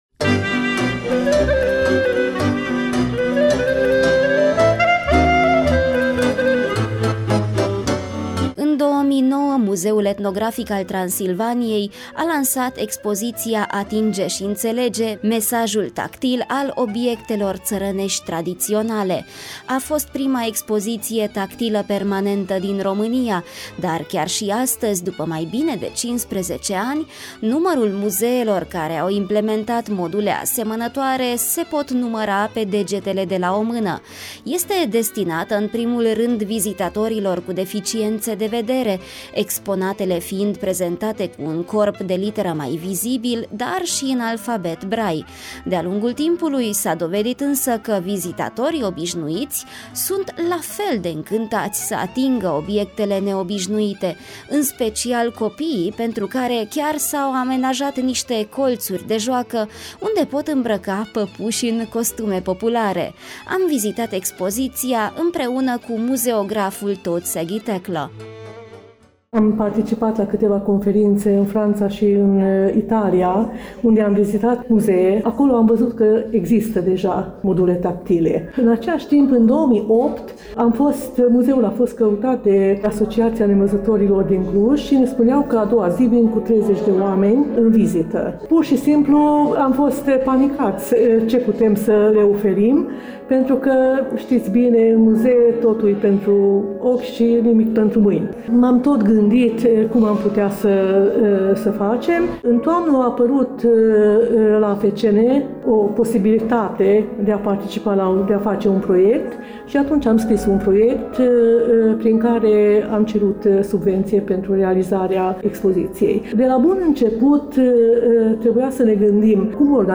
Vă invităm să ascultaţi înregistrarea audio, pentru a străbate cu noi expoziţia pentru nevăzători a Muzeului Etnografic al Transilvaniei.